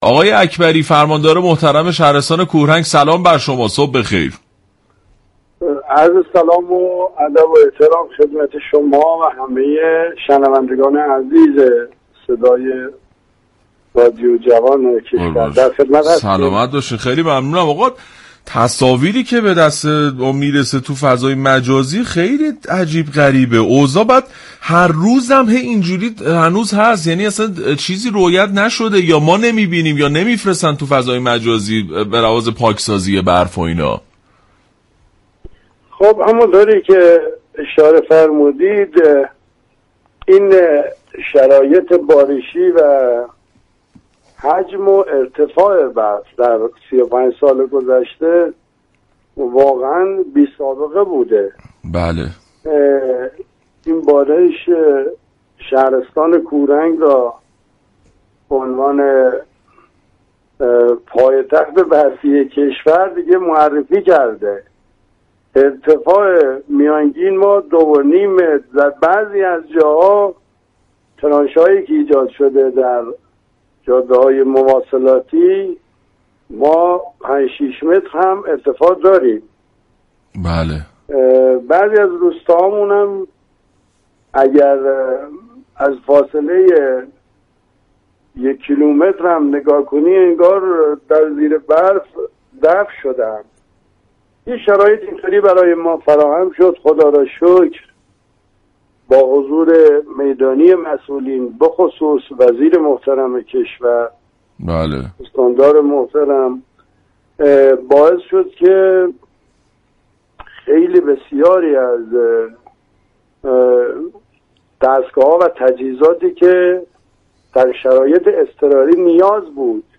شهباز اكبری، فرماندار شهرستان كوهرنگ در گفتگو با برنامه جوان ایرانی سلام اظهار كرد: حجم و ارتفاع بارش برف در 35 سال گذشته واقعا بی سابقه بوده است و به ارتفاع میانگین دو و نیم متر و در بعضی از مناطق 5 الی 6 متر هم ارتفاع بسیار بالا بوده است.